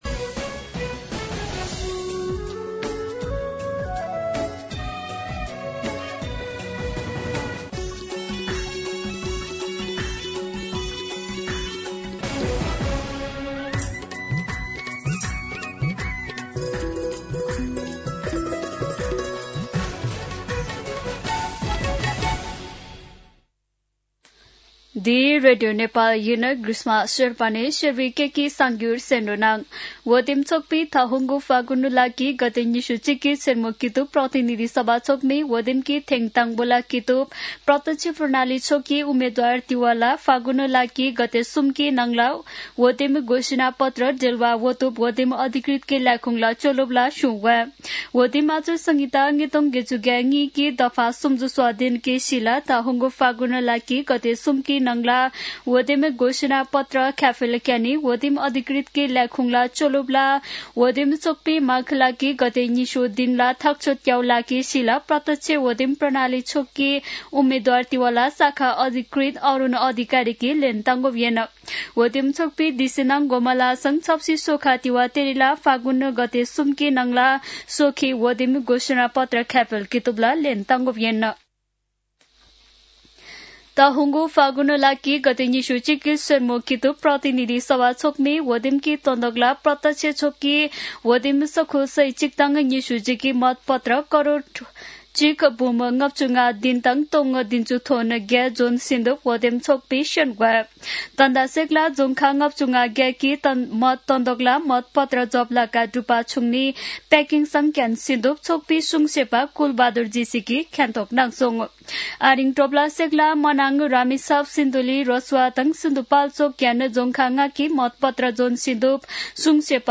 An online outlet of Nepal's national radio broadcaster
शेर्पा भाषाको समाचार : २९ माघ , २०८२
Sherpa-News-10-29.mp3